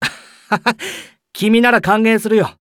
文件 文件历史 文件用途 全域文件用途 Ja_Bhan_tk_02.ogg （Ogg Vorbis声音文件，长度2.6秒，104 kbps，文件大小：33 KB） 源地址:游戏语音 文件历史 点击某个日期/时间查看对应时刻的文件。 日期/时间 缩略图 大小 用户 备注 当前 2018年5月25日 (五) 03:00 2.6秒 （33 KB） 地下城与勇士  （ 留言 | 贡献 ） 分类:巴恩·巴休特 分类:地下城与勇士 源地址:游戏语音 您不可以覆盖此文件。